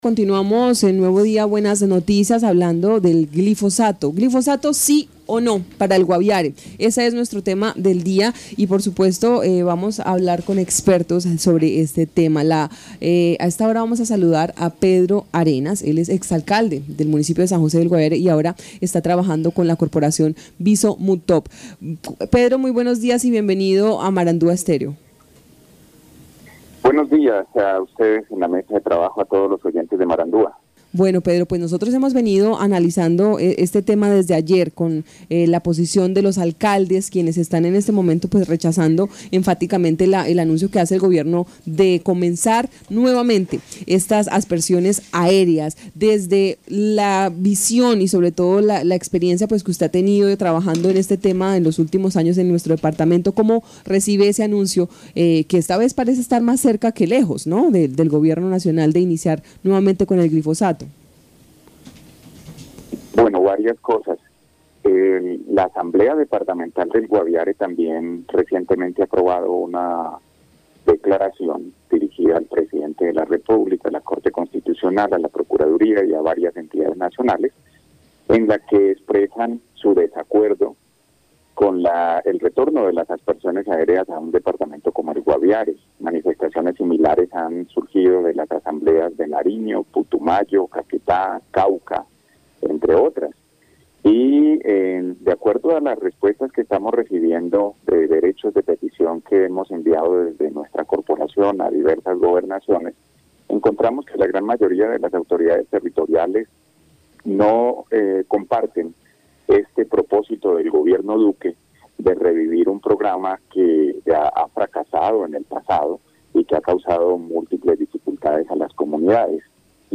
Escuche a Pedro Arenas, experto de la Corporación Viso Mutop.
Pedro José Arenas García, exalcalde de San José del Guaviare y experto de la Corporación Viso Mutob.